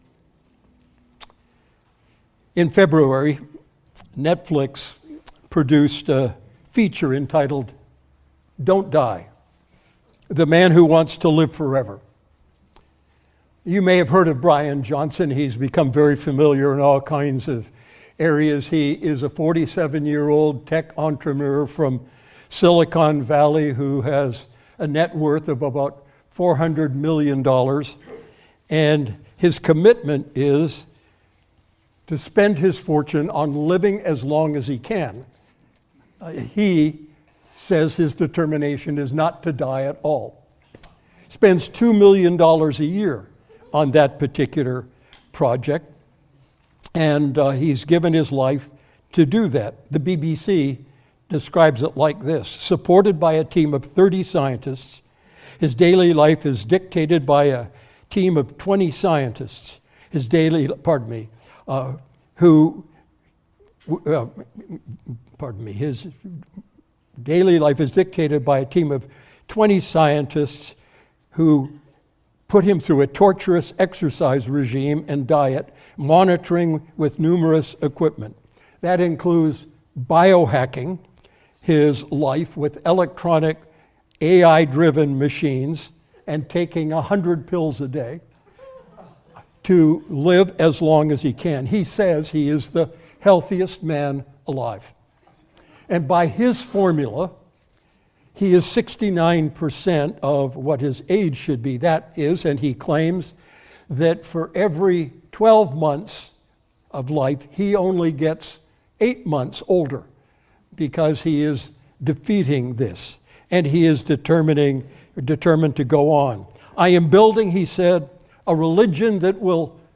Standalone Sermon